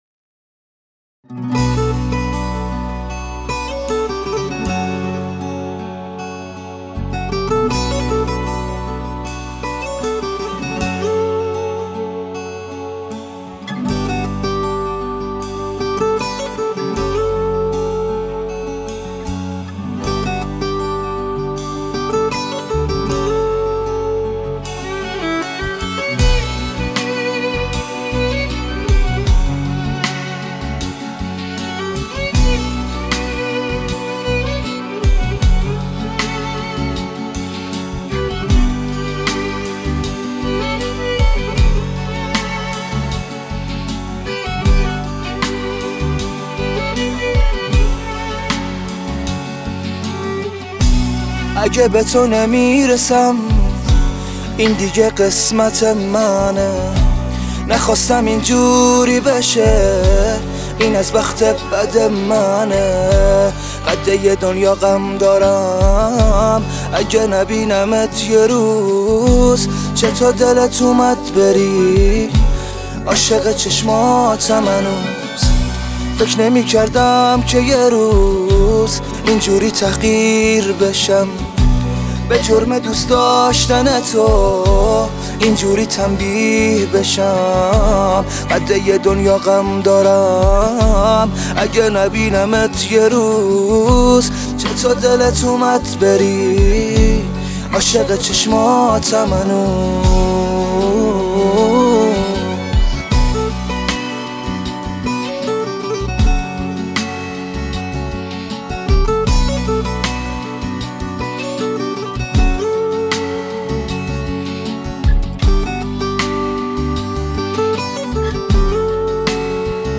بسیار احساسی و غمگین